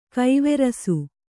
♪ kaiverasu